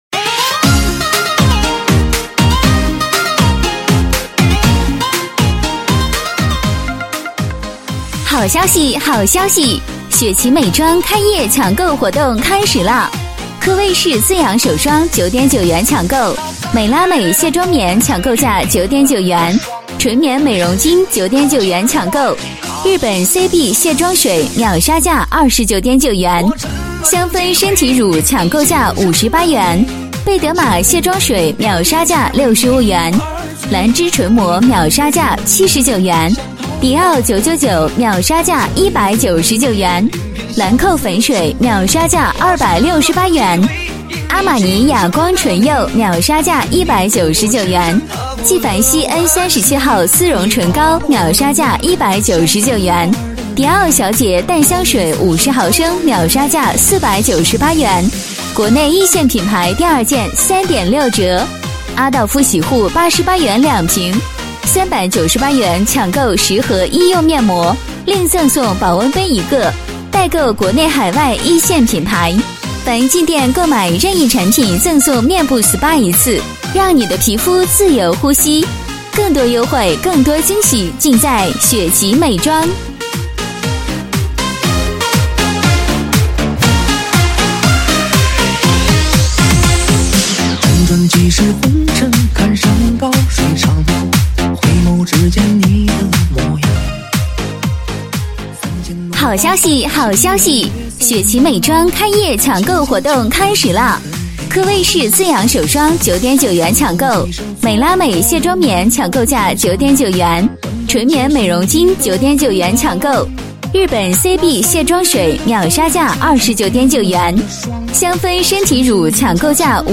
响亮有力的广告音频制作